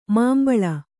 ♪ māmbaḷa